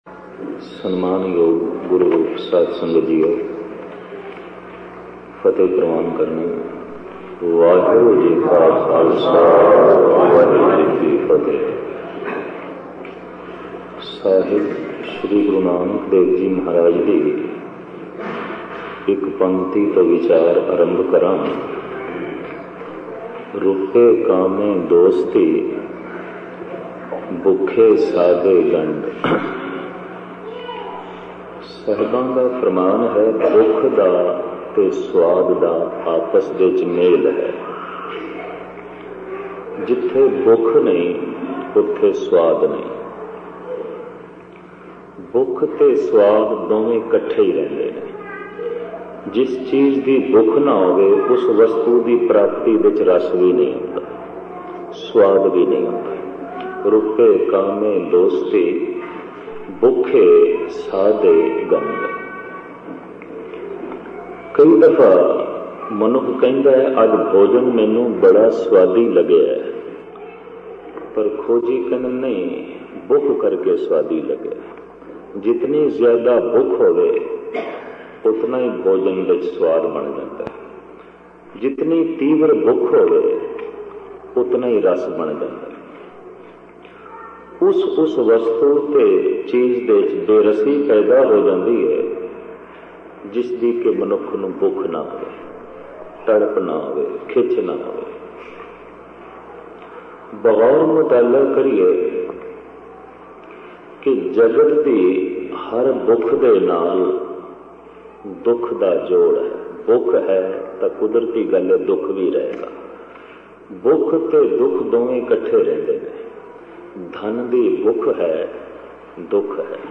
Katha
Roope Kame Dosti Genre: Gurmat Vichar